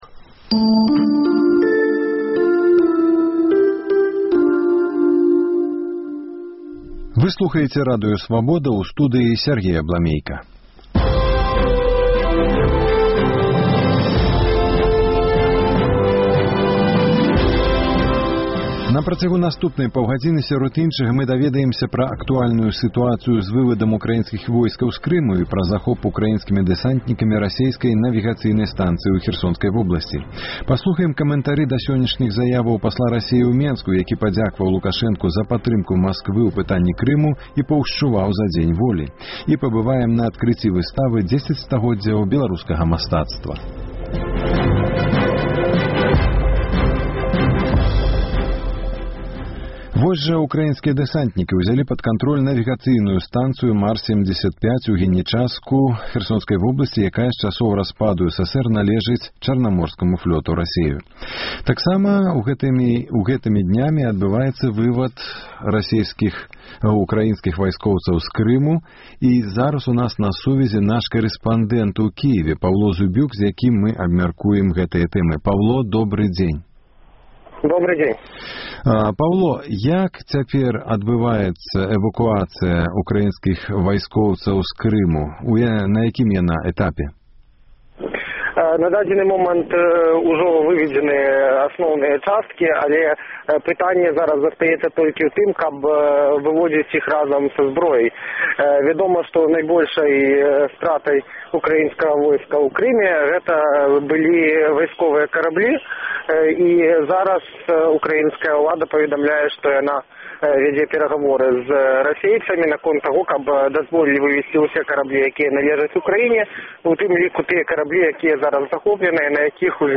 Украінскія дэсантнікі ўзялі пад кантроль навігацыйную станцыю «Марс-75» у Генічаску Херсонскай вобласьці, якая з часоў распаду СССР належыць Чарнаморскаму флёту Расеі. Рэпартаж з Украіны.